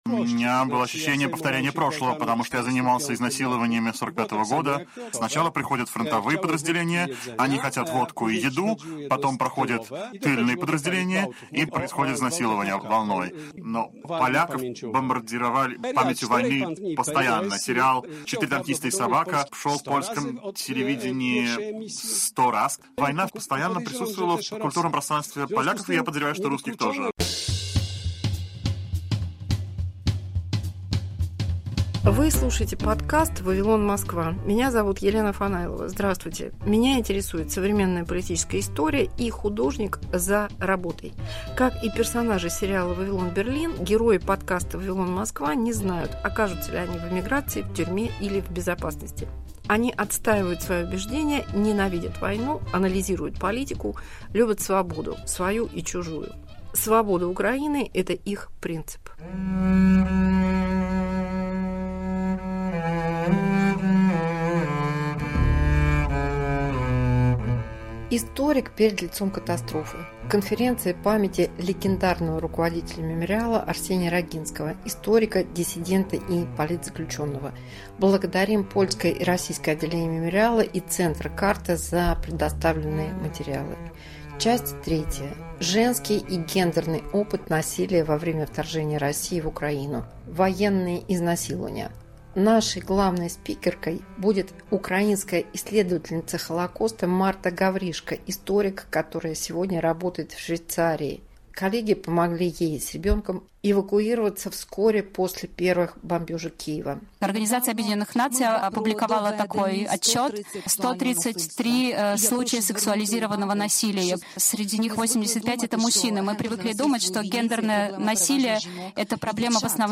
Преступления российских военных в Украине. Доклад исследовательницы Холокоста на конференции памяти Арсения Рогинского, основателя Общества Мемориал